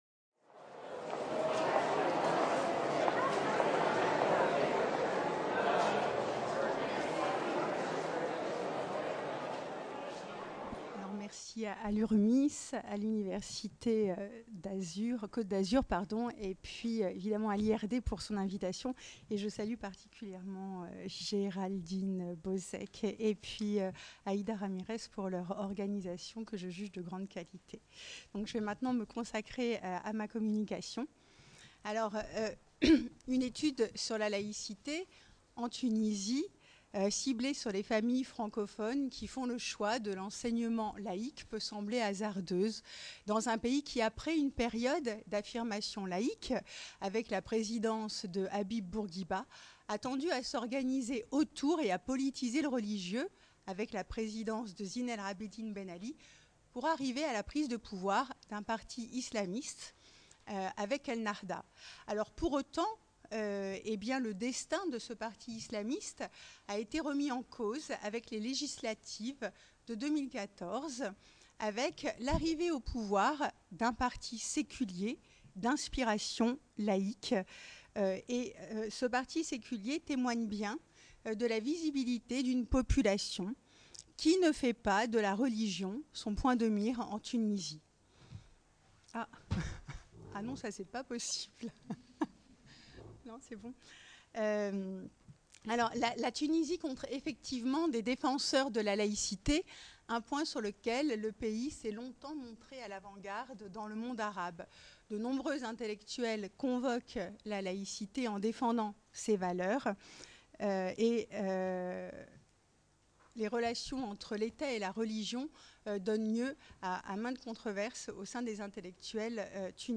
Journée d’études organisée par l’Urmis, Université Côte d’Azur, IRD, le jeudi 13 décembre 2018 à Nice.